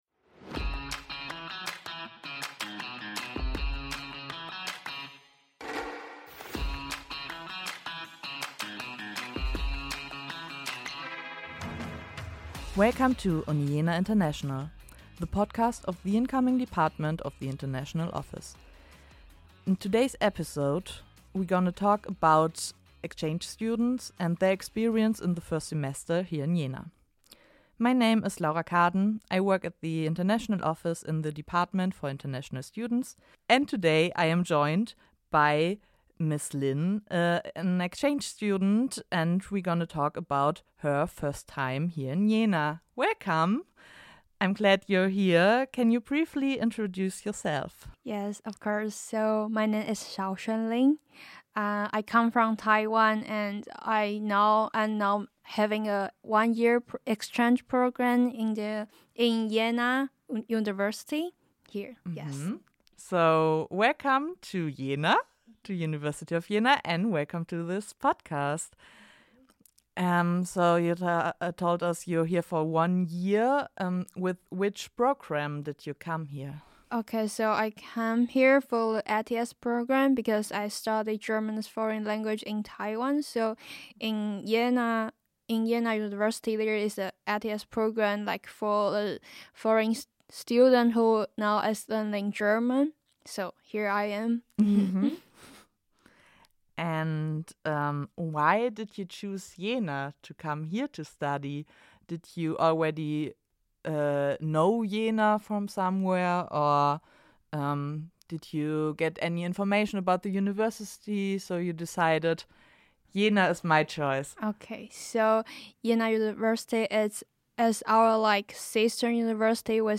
In this episode an exchange student talks about her experiences at the beginning of her stay in Jena and the start of the semester. How to prepare? What was a suprise? How is studying in Germany different?